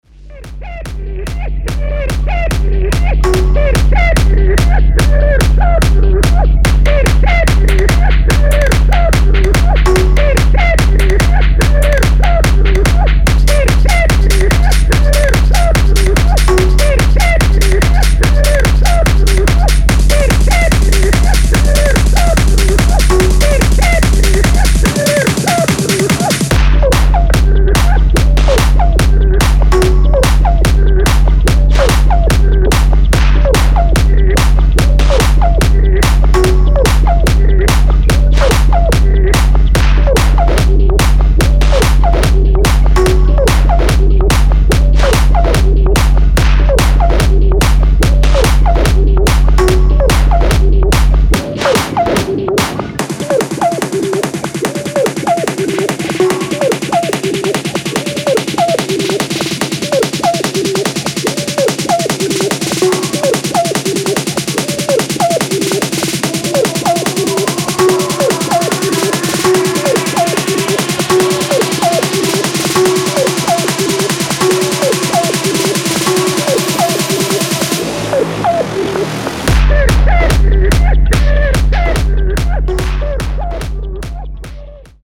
Electronic
Techno, Hard Techno, Hardstyle, Jumpstyle